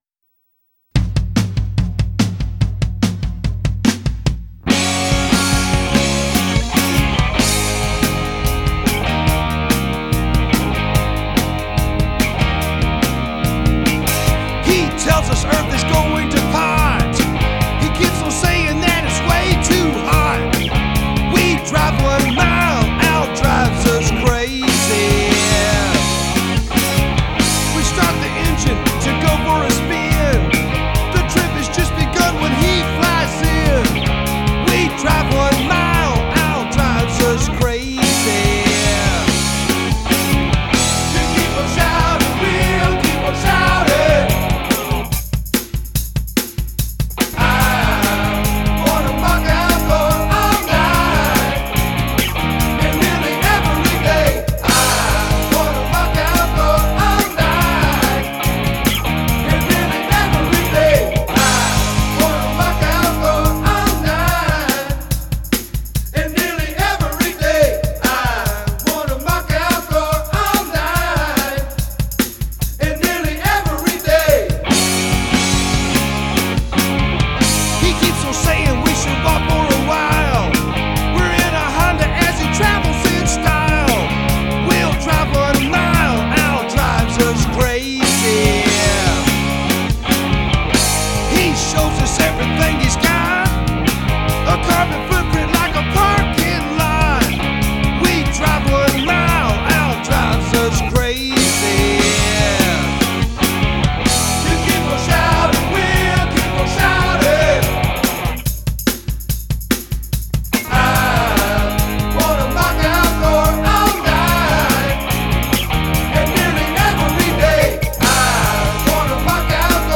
I listened to them first (I think beer might have been involved during the recording session).